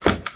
STEPS Wood, Reverb, Run 01, Creak.wav